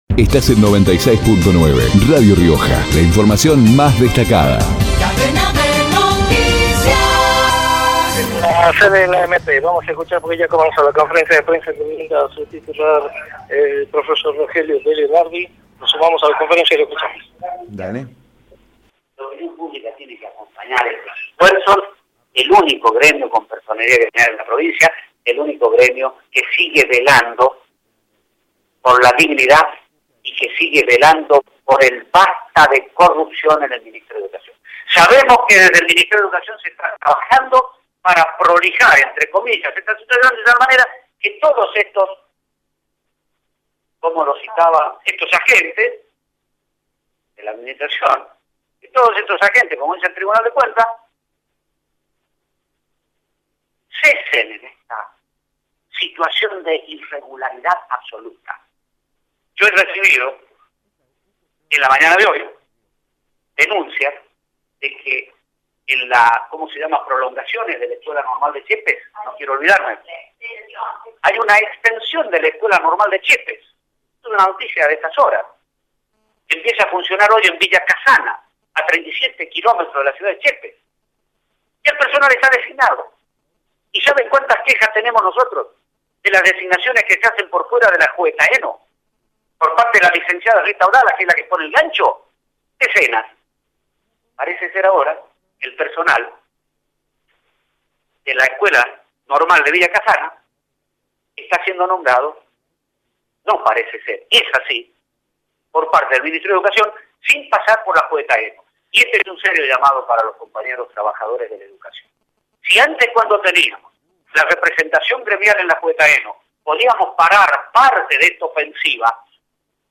por Radio Rioja